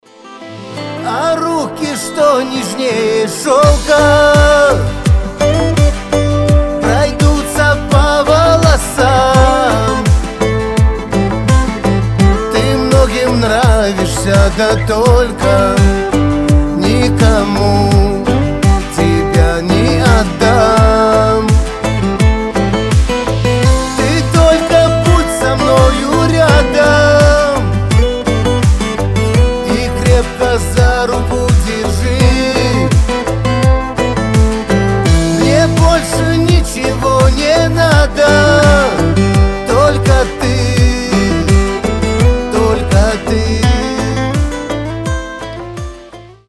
гитара
медленные